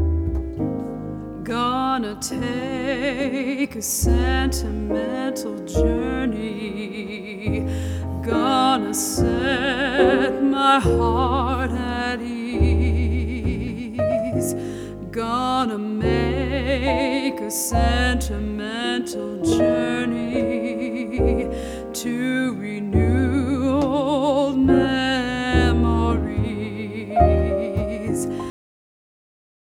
Sample These Song Snippets from the Album